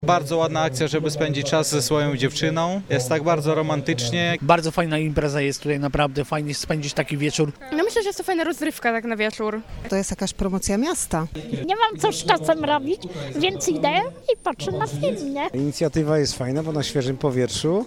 Zapytaliśmy uczestników wydarzenia, co sądzą o takiej formie spędzania wakacyjnych wieczorów.